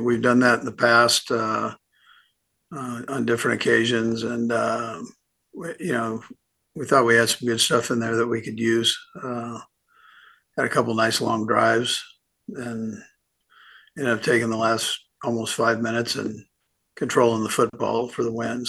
Andy Reid press conference